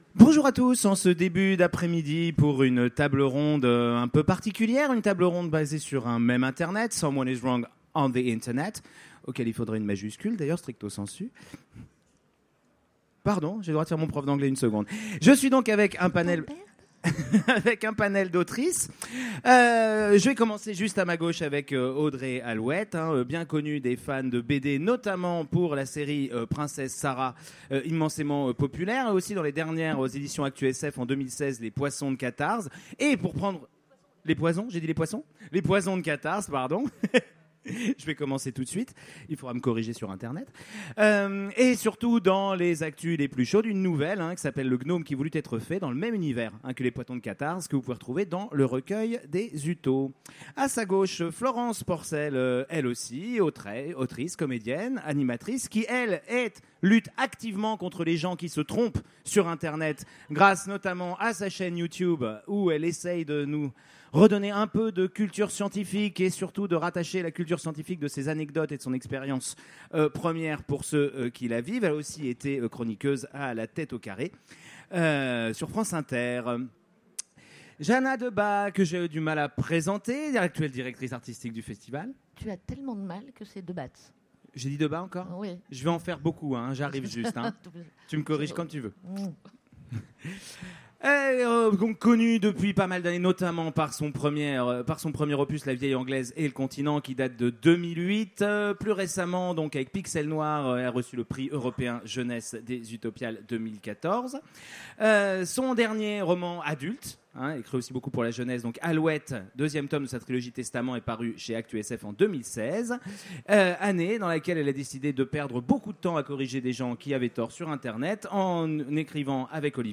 Utopiales 2017 : Conférence Someone is wrong on the internet